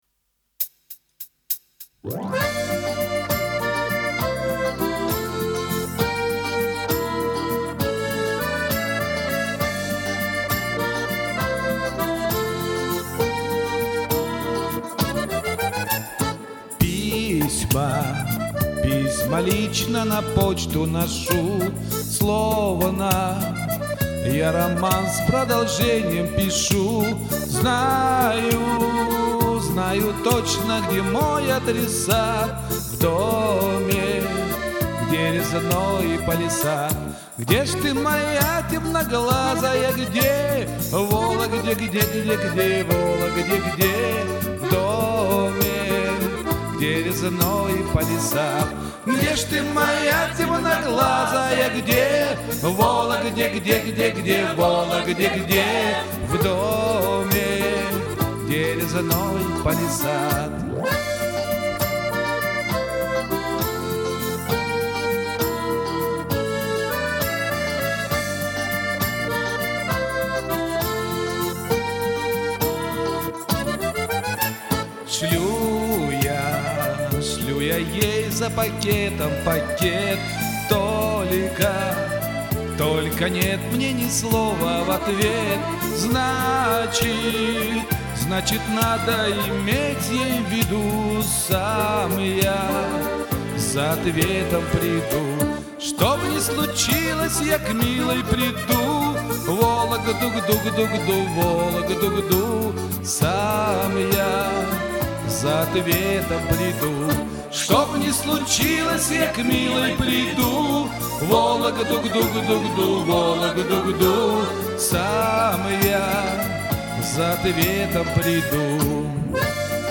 И фанера извините караоке!